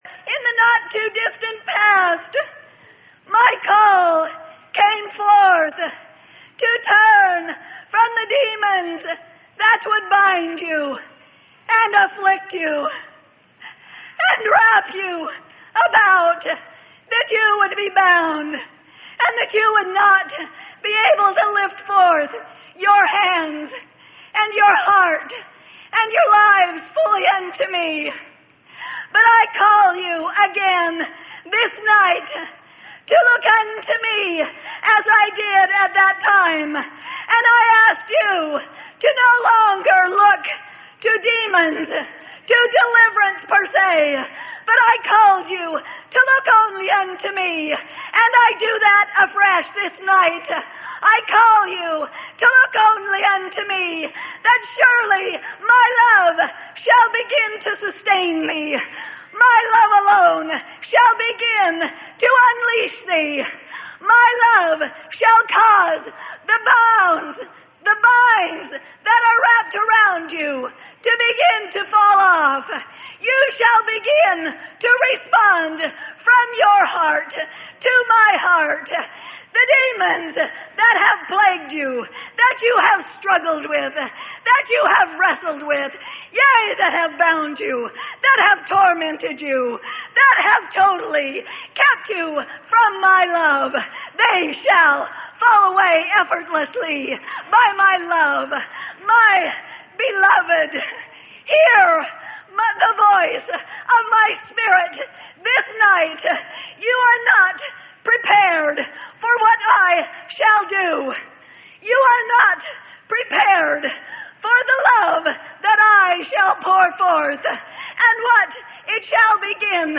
Sermon: Static or Dynamic: Which Will You Be?